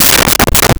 Door Knock With Open Hand
Door Knock with Open Hand.wav